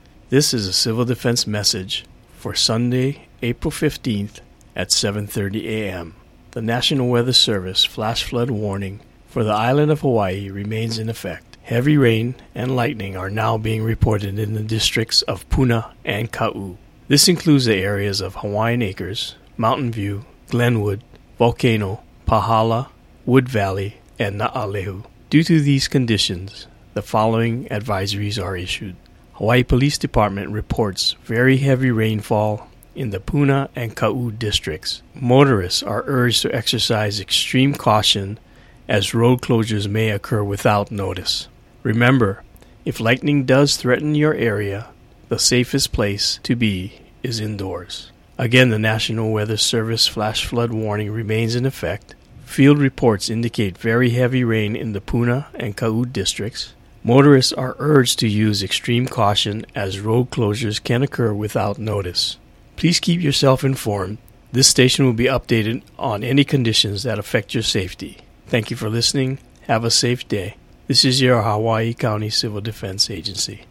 Hawaii County Civil Defense 7:30 a.m. audio message